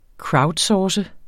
Udtale [ ˈkɹɑwdˈsɒːsə ]